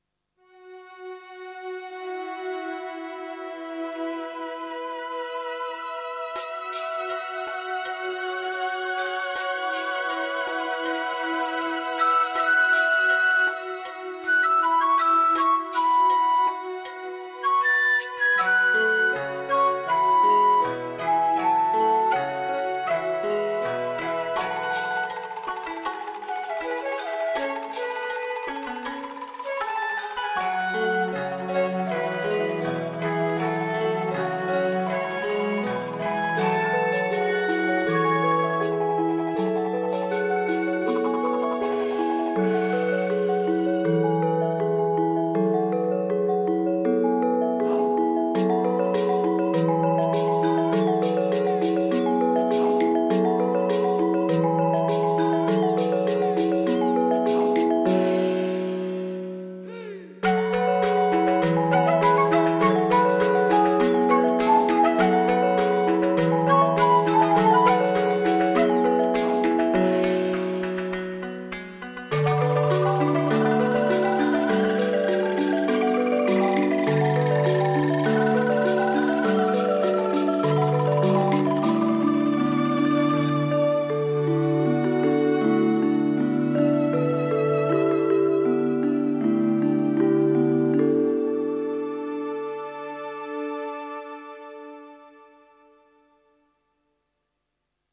Hierbij een aantal fragmenten op basis van inheemse en folkloristische instrumenten.
Familiereünie Aziatisch/Indisch 1:38 1.4mb 2009 Intromuziek voor de website van onze Indische familiereünie. Hierbij zijn instrumenten als de gamelan en angklung gebruikt.